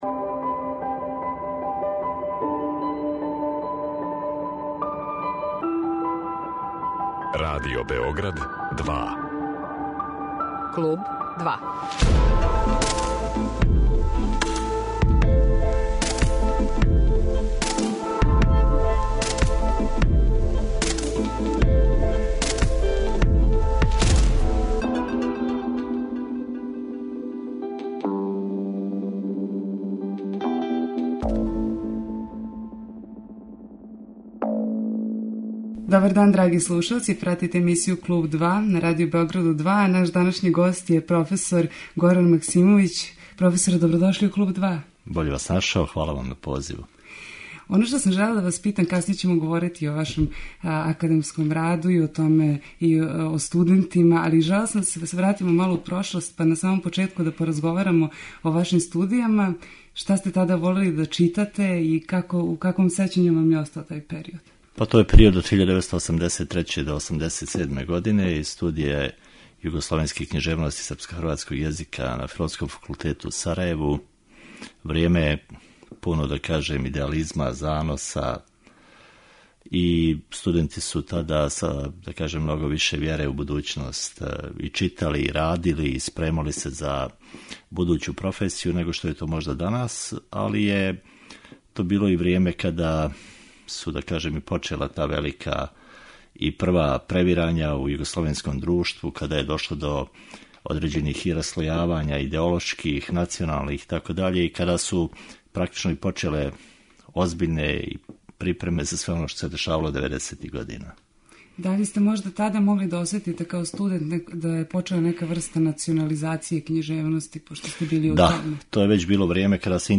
Са професором разговарамо о студијама, о предавачком и научно истраживачком раду али и ономе на чему тренутно ради.